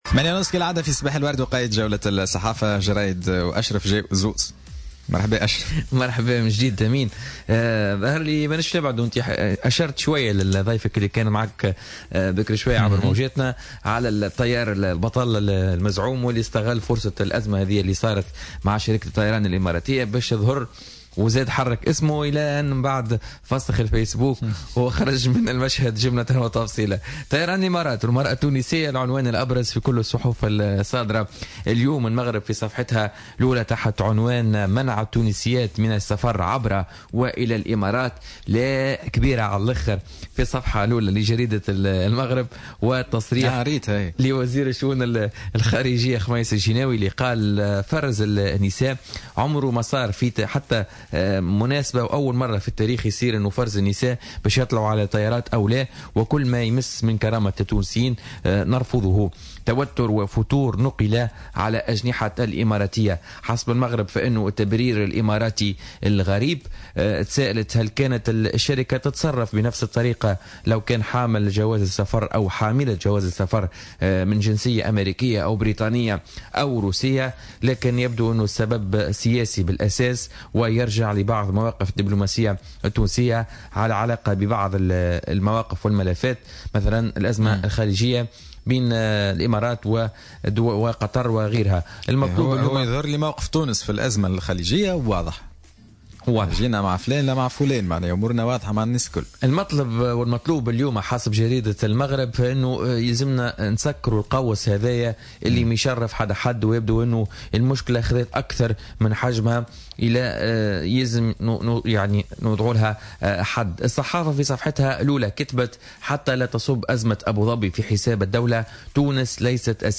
Revue de presse du mardi 26 décembre 2017